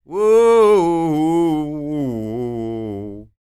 Blues Soul